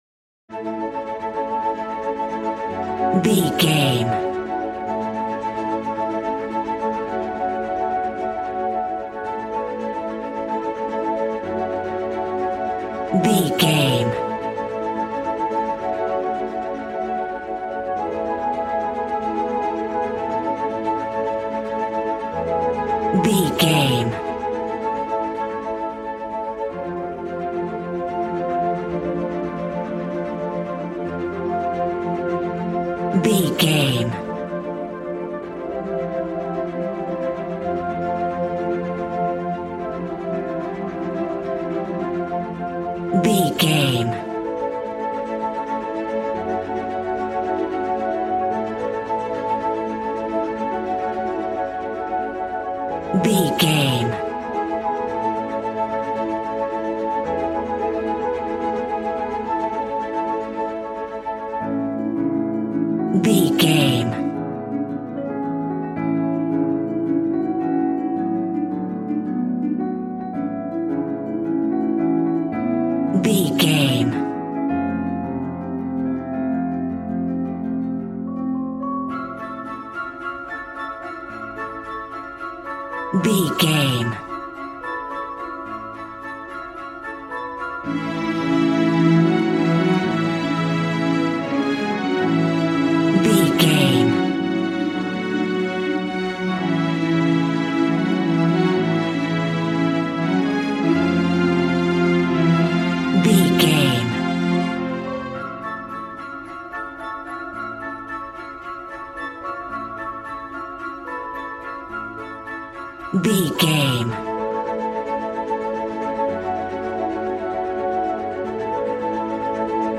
Regal and romantic, a classy piece of classical music.
Ionian/Major
strings
violin
brass